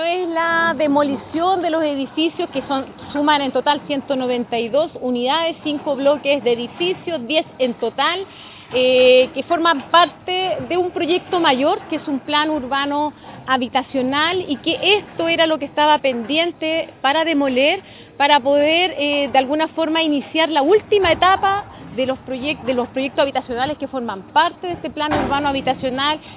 La seremi de vivienda, Claudia Toledo, entregó más detalles respecto de la cantidad de departamentos que estaban en desuso, debido a que no era posible vivir en ellos.